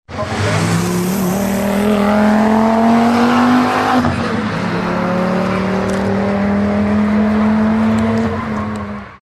Sons de moteurs volvo - Engine sounds volvo - bruit V8 V10 volvo